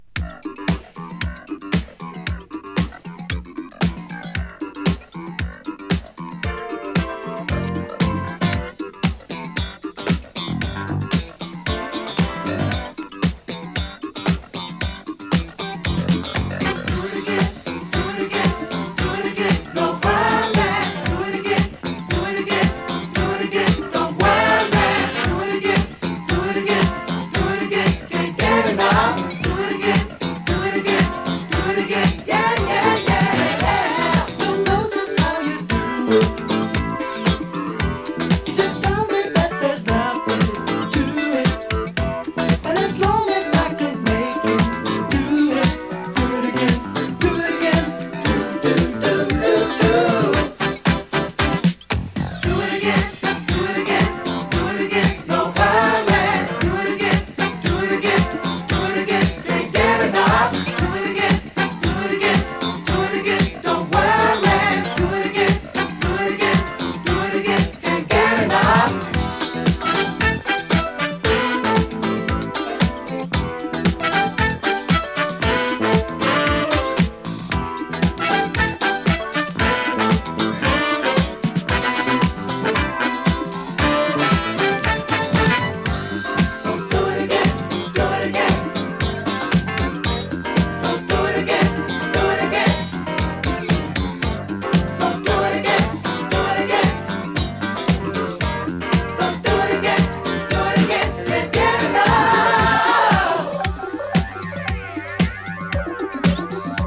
ダッチ ファンク ガラージュ ディスコ